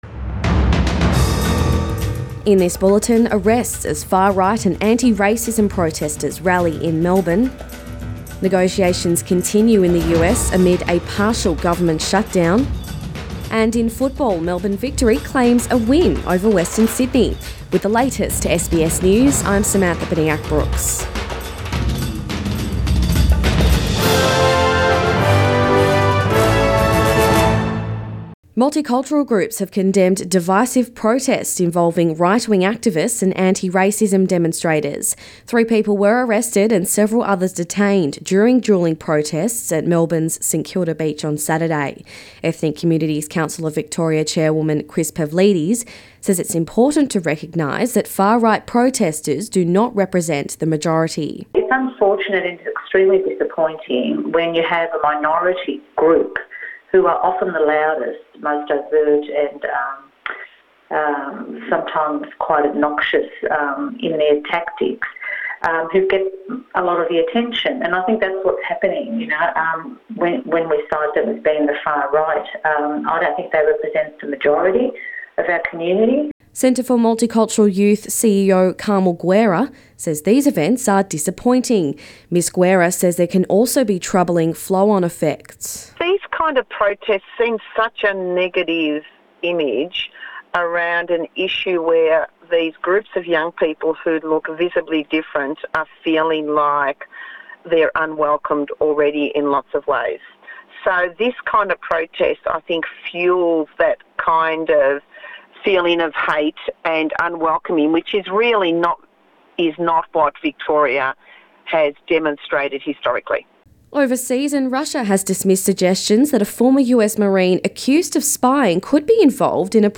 AM bulletin 6 January